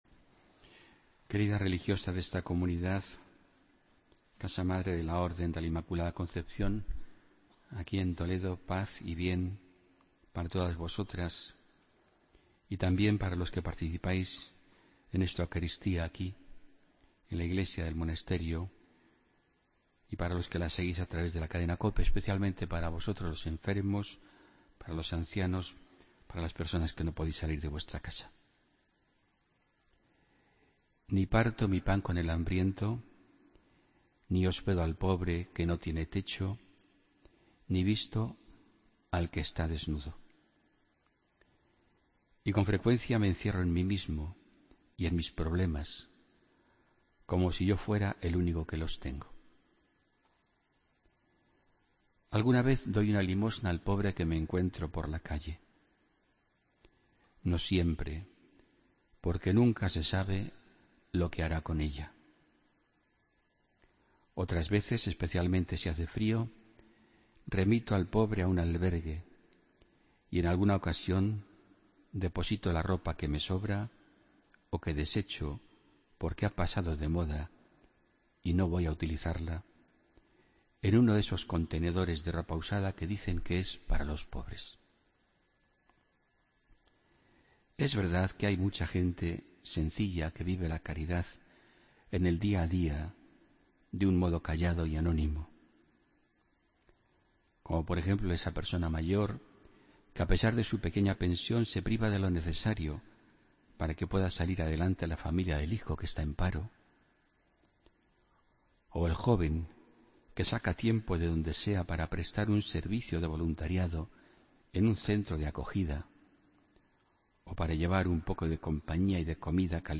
Homilía del domingo 5 de febrero de 2017